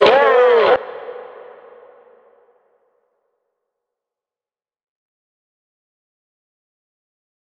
DMV3_Vox 2.wav